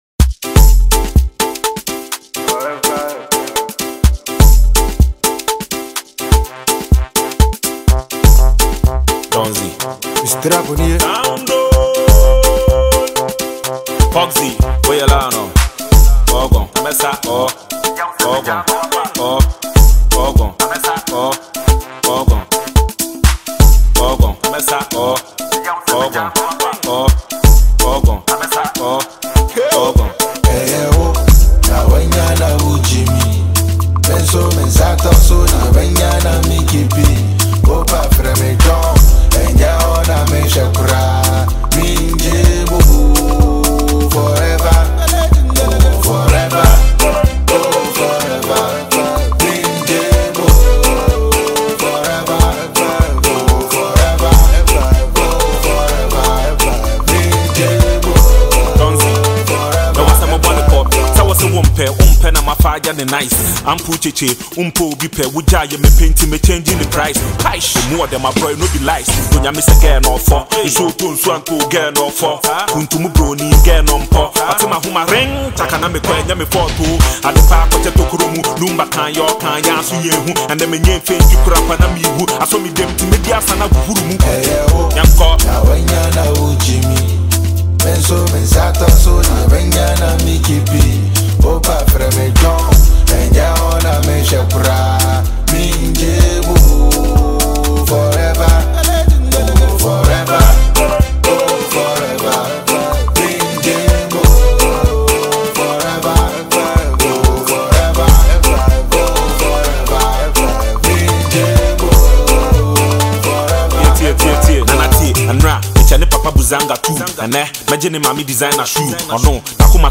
smooth Afrobeat rhythms with heartfelt emotions